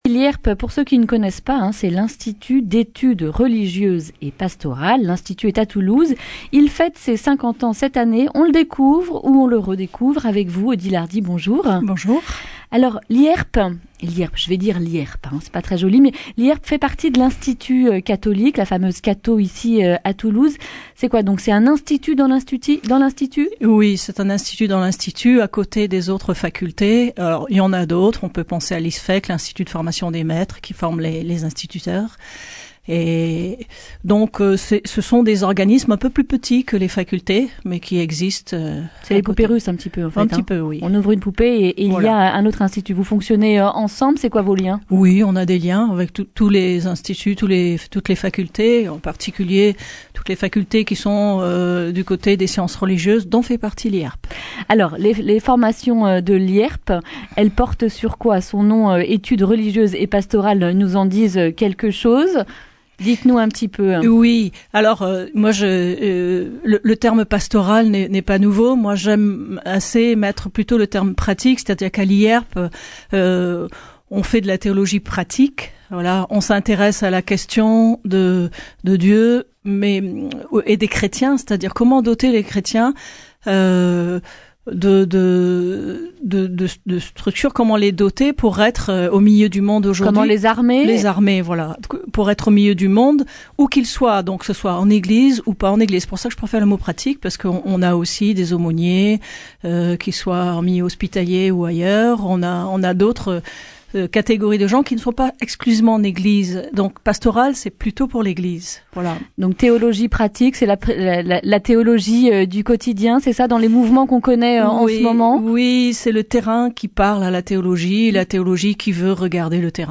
jeudi 26 septembre 2019 Le grand entretien Durée 11 min